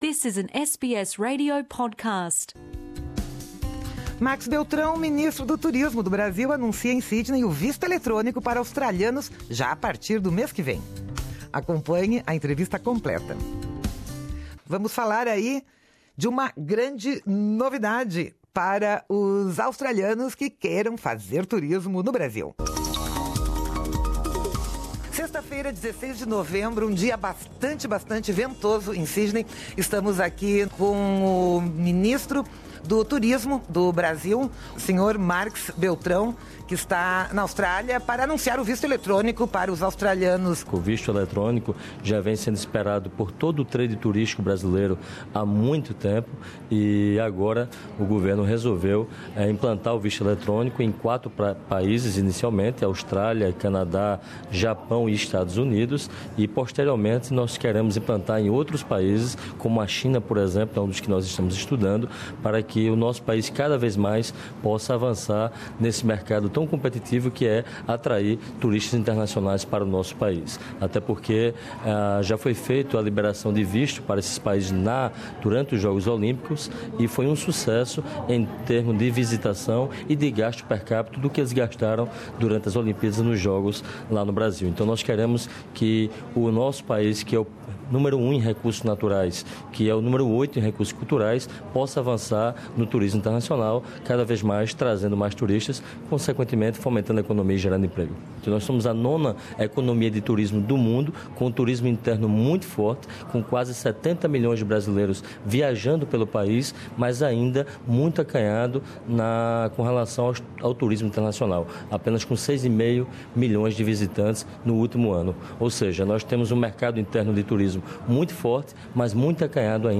Ouça aqui a íntegra da entrevista que o ministro Marx Beltrão deu ao Programa Português da Rádio SBS, falando das vantagens deste tipo de visto, do que o Brasil tem a aprender com a Austrália em matéria de marketing para o turismo e como enfrentar o problema de vender turismo brasileiro com da criminalidade e a violência no país, o que faz com que muitos estrangeiros pensem duas vezes antes de escolher o Brasil para as suas próximas férias.